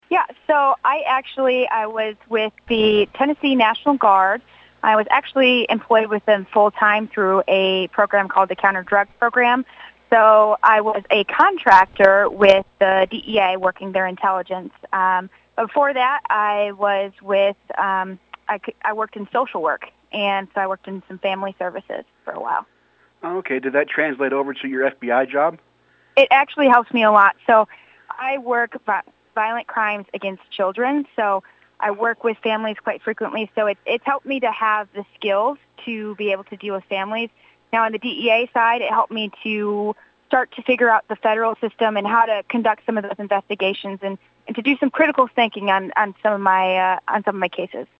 the Cromwell News Team held a phone interview